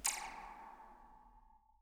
Percussion
zap1_v1.wav